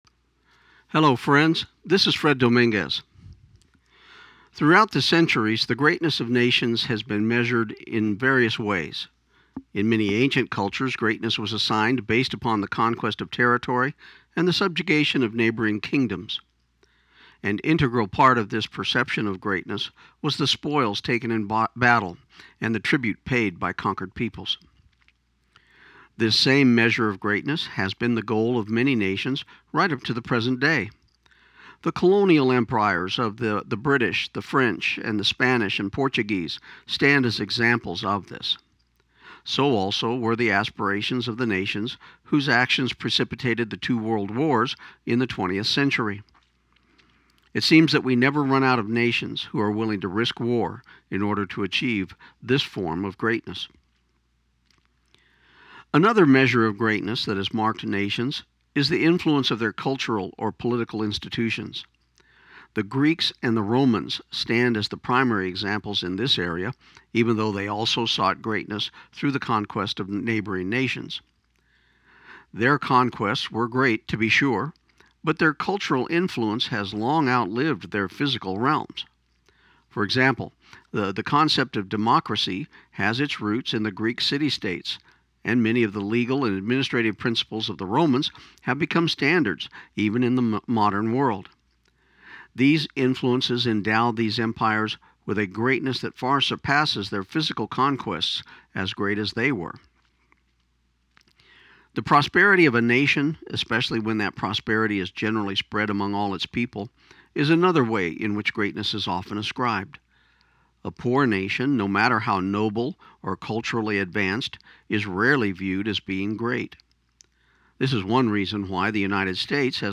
This program aired on KIUN 1400 AM in Pecos, TX on July 4, 2016.